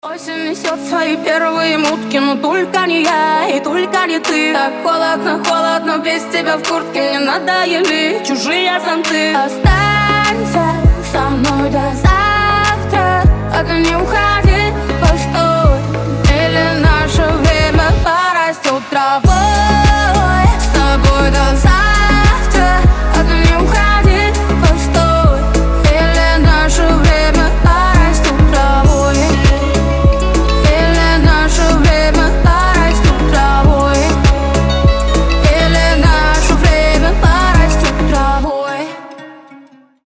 поп
RnB